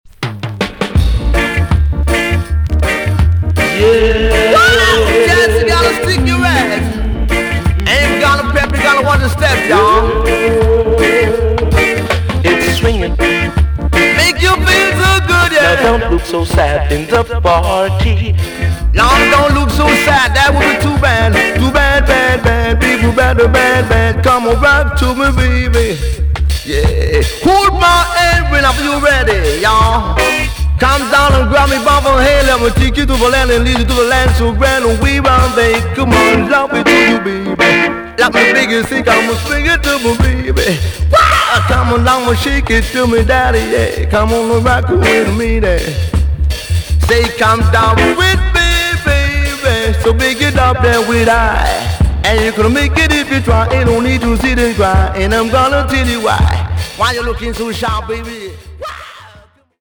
TOP >REGGAE & ROOTS
EX- 音はキレイです。